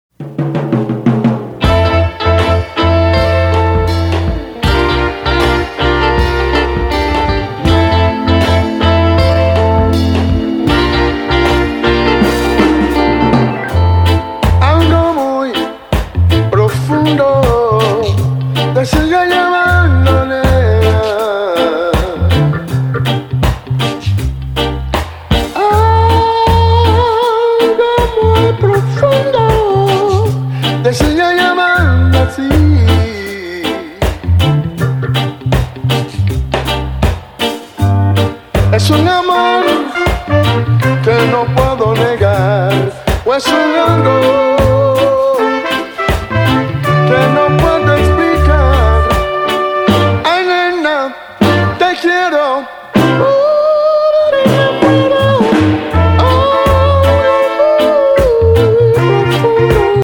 トロピカルでハートフルな音源をゆるく紡いでいった極上の一枚、これはタイムレスに楽しめること間違いなしですね！